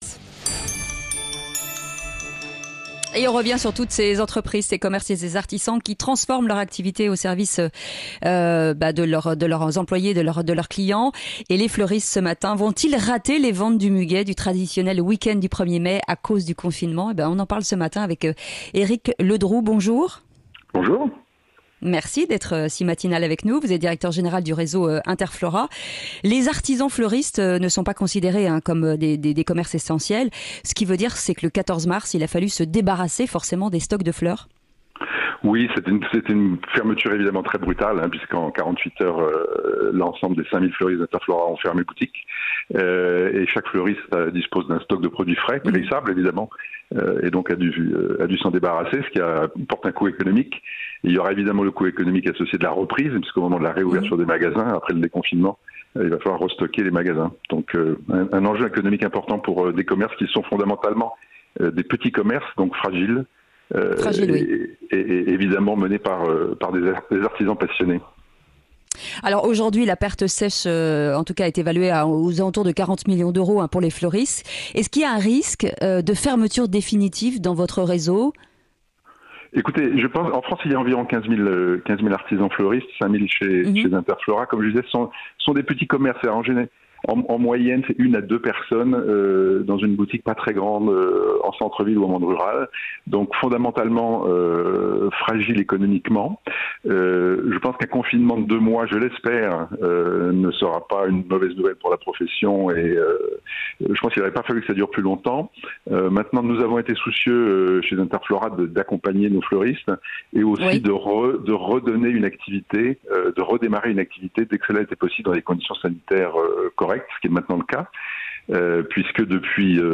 Sud Radio à votre service dans le grand Matin Sud Radio à 6h50 avec FIDUCIAL.